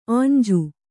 ♪ āñju